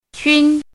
怎么读
qūn
qun1.mp3